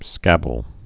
(skăbəl)